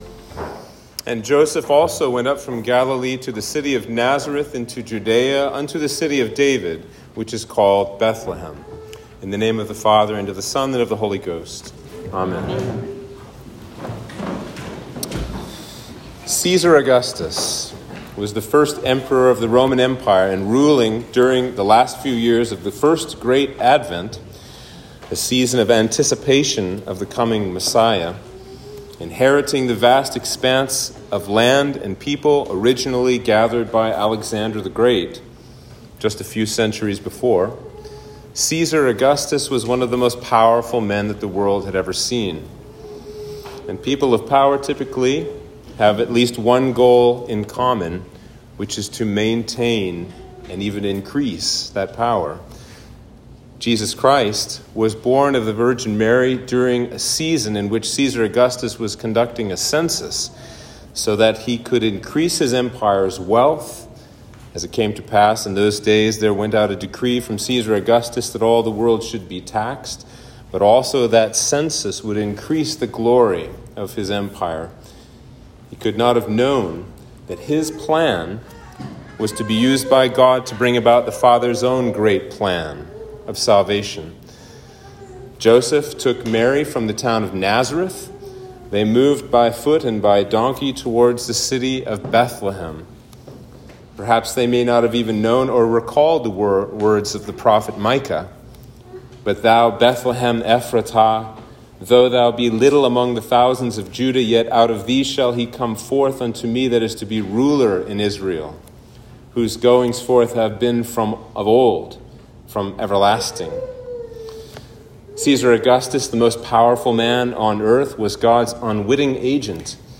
Sermon for Christmas Eve